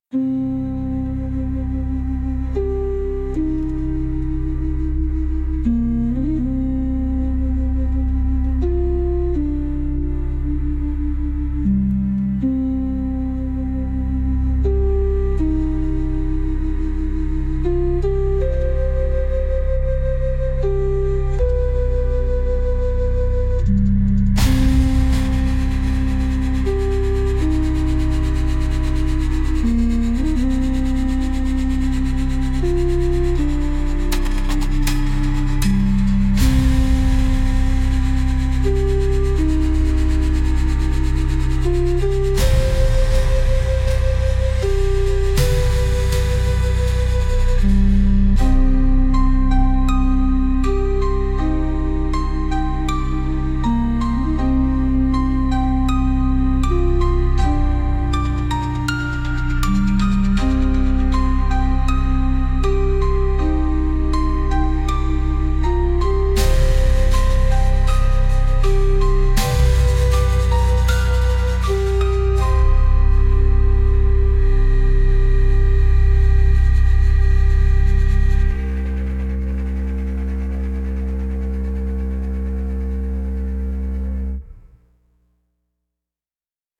Instrumental- Glass Veins 1. 26 sec